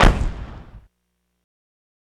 Waka KICK Edited (44).wav